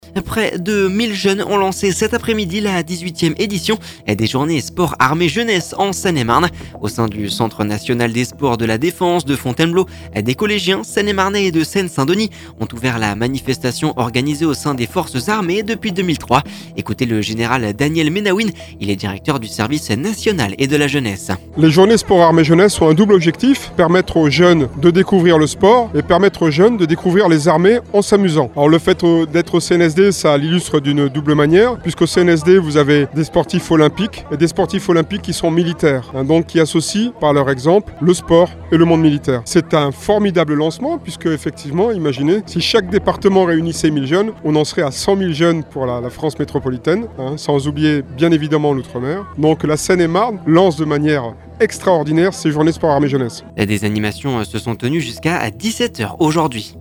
Général Daniel Ménaouine, directeur du service national et de la jeunesse…